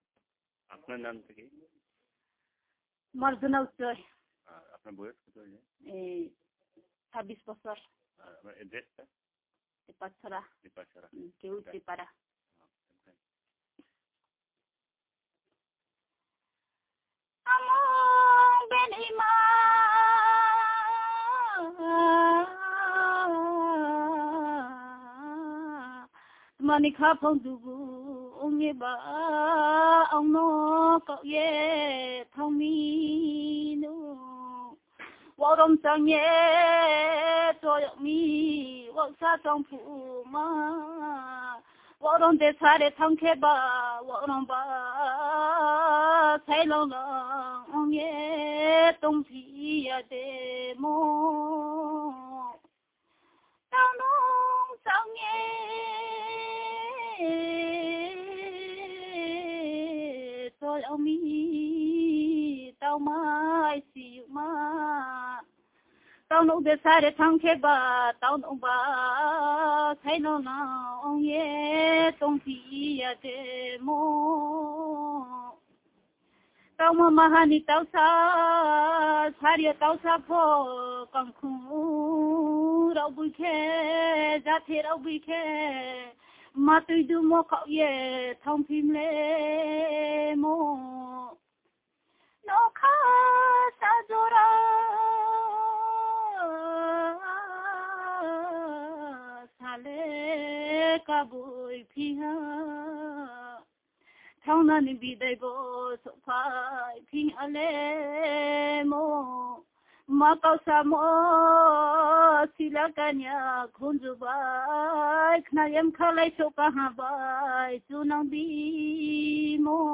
Performance of Uchoi folk song.